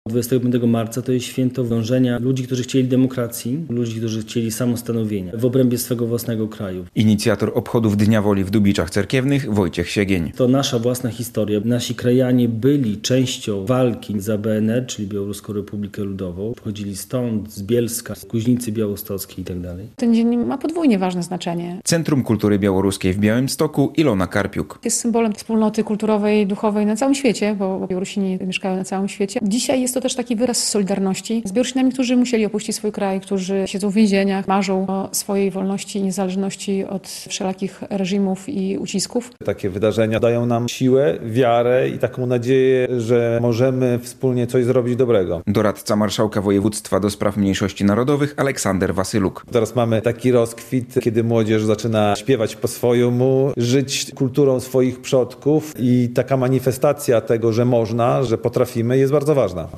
Obchody Dnia Woli - relacja